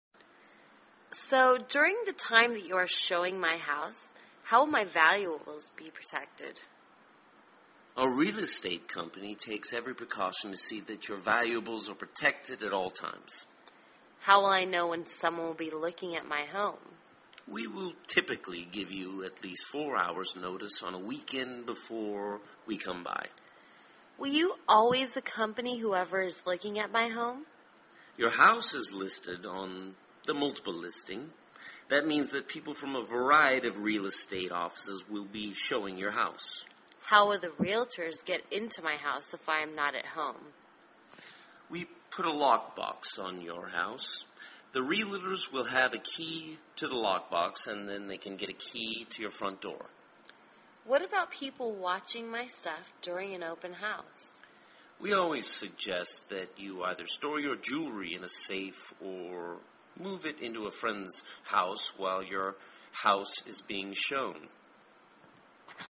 卖房英语对话-Home Security During Listing(1) 听力文件下载—在线英语听力室